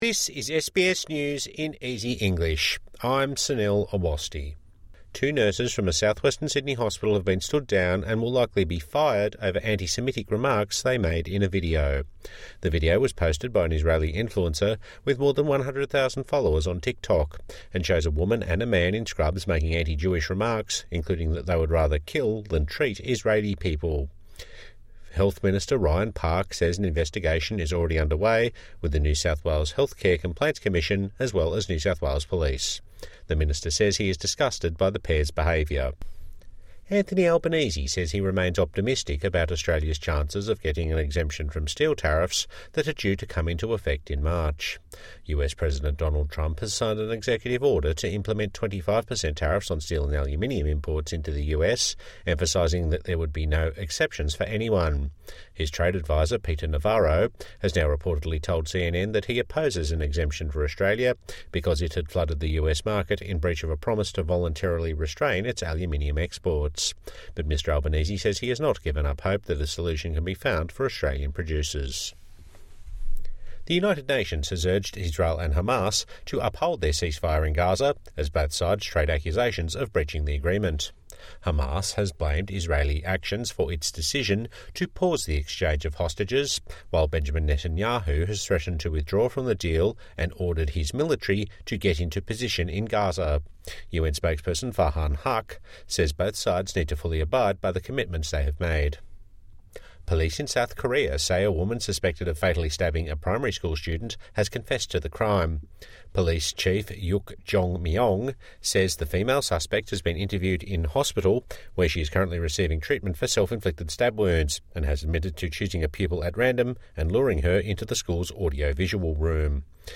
A five-minute news wrap for those with disability and those learning English